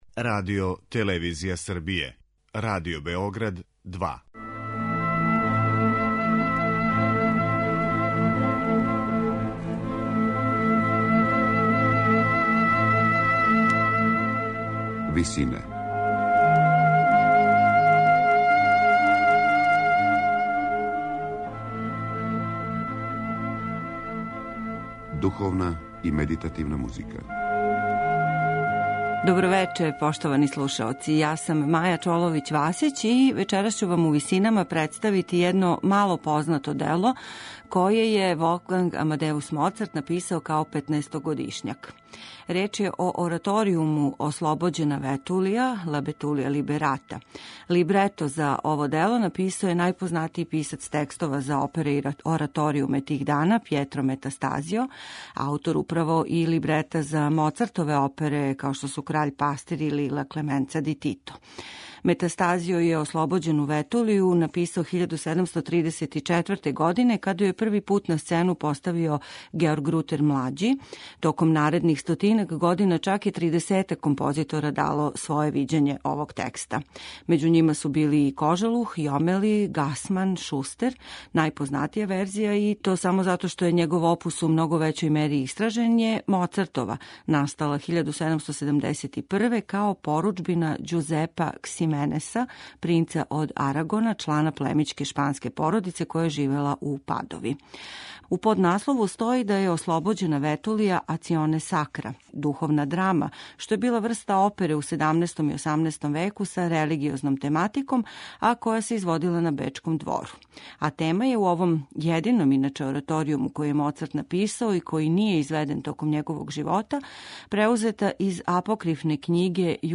ораторијума
арија и хорских ставова
Диригент је Леополд Хагер.